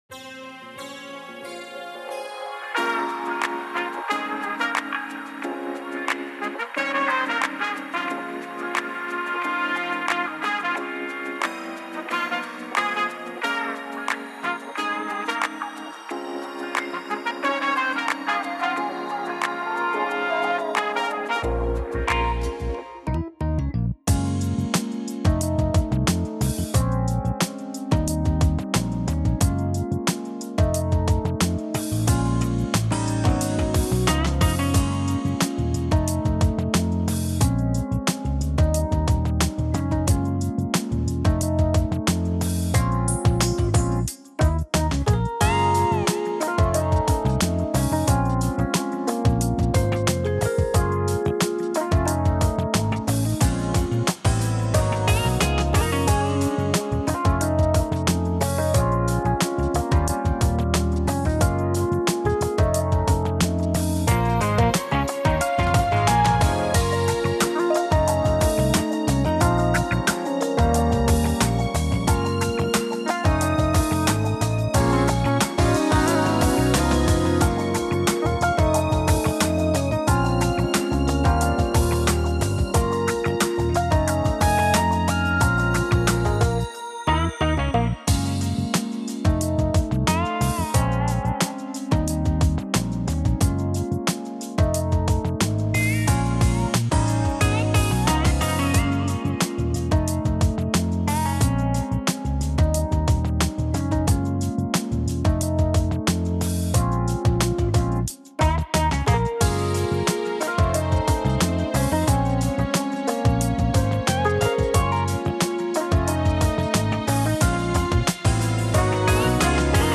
минусовка версия 239122